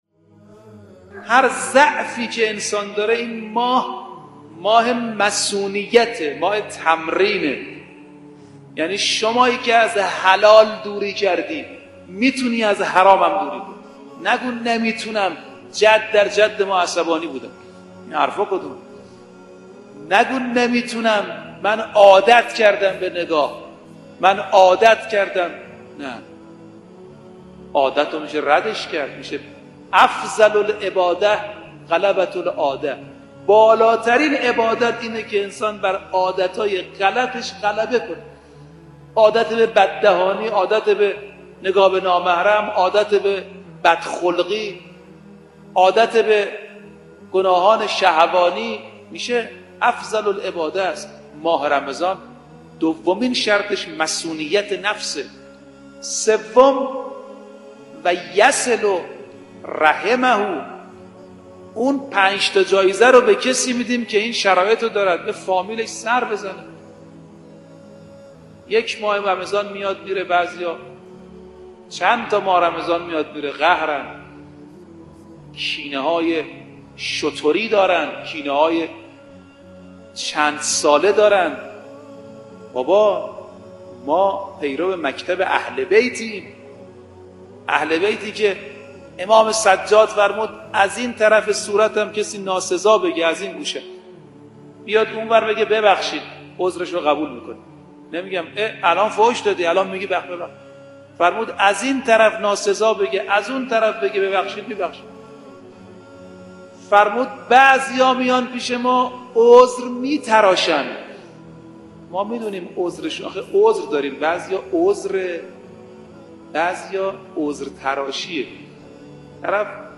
در یکی از سخنرانی‌های خود